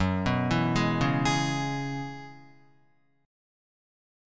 Gb9sus4 chord